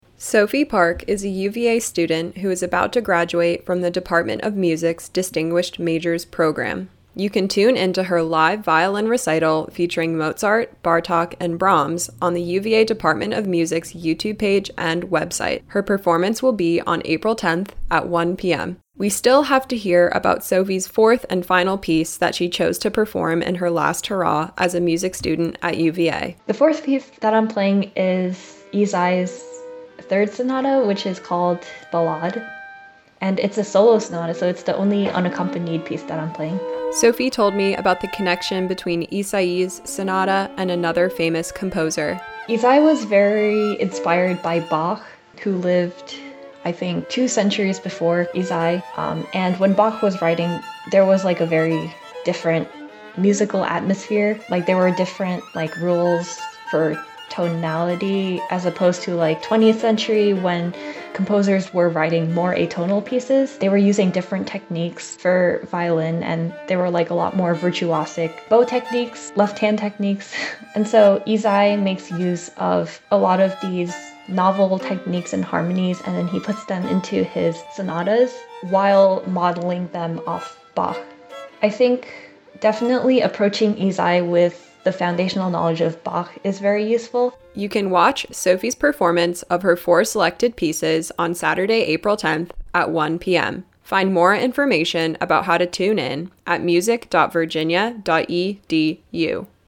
Classical Interviews
These interviews air as part of WTJU’s Classical Sunrise, weekday mornings from 7-9am.